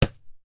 short hit 01
bonk effect fist hit impact short thud sound effect free sound royalty free Sound Effects